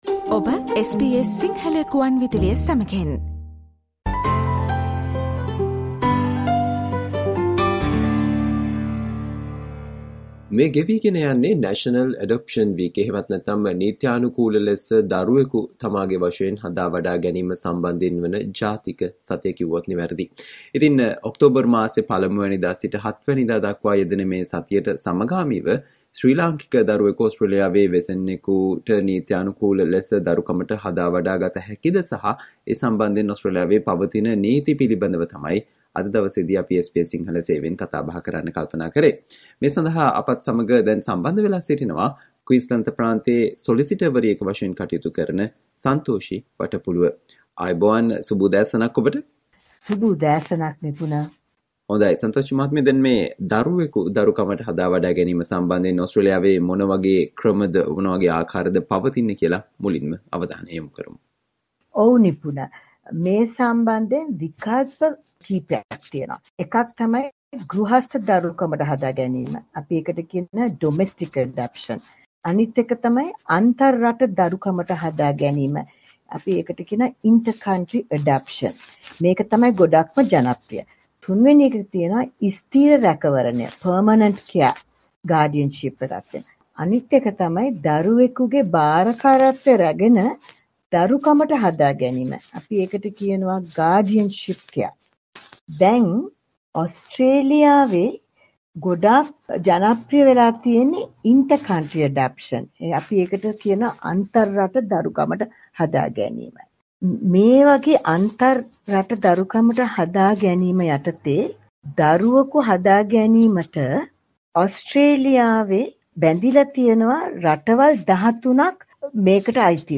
Listen to the SBS Sinhala discussion on Important things you should know to legally adopt a child from Sri Lanka